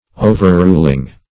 Overruling \O`ver*rul"ing\, a.